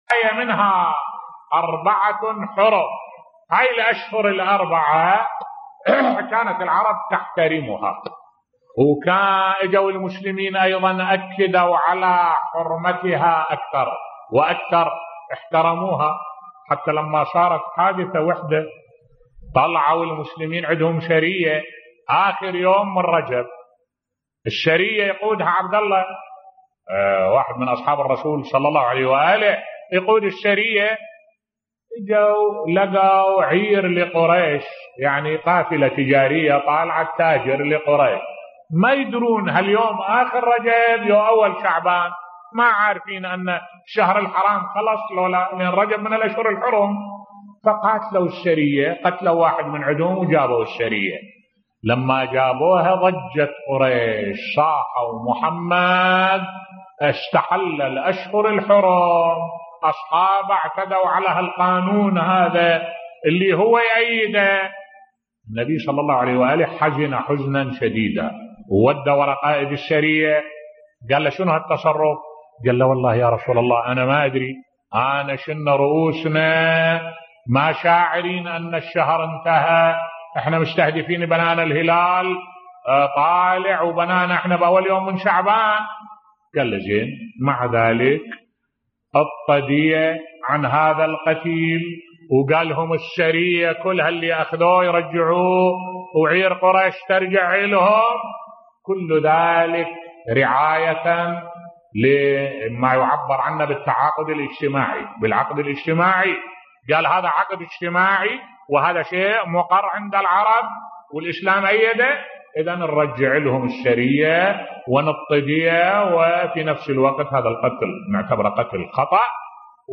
ملف صوتی عاشوراء ليست بكاء و لطم فقط بصوت الشيخ الدكتور أحمد الوائلي